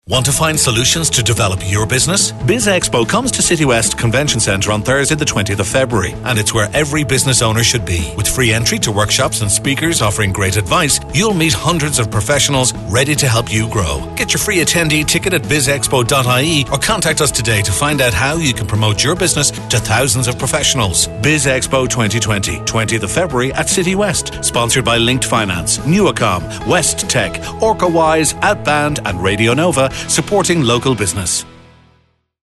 Here is a selection of our radio commercials below: